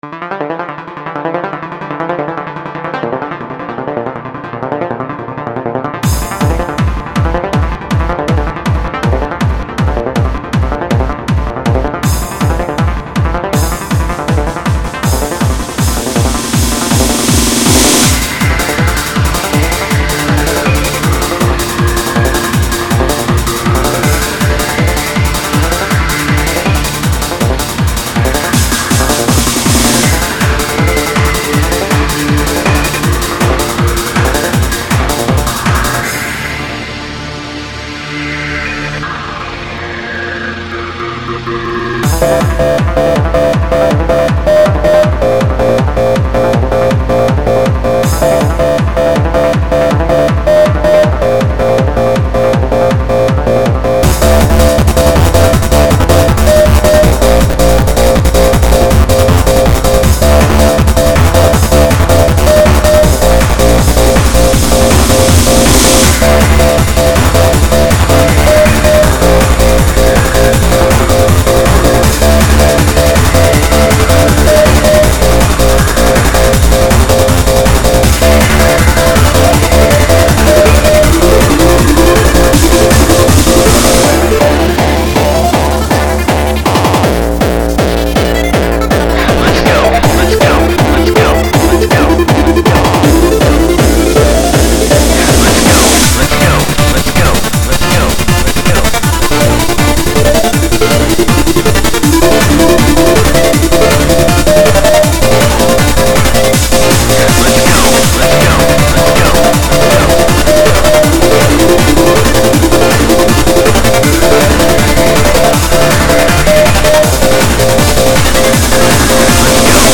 An instrumental recording.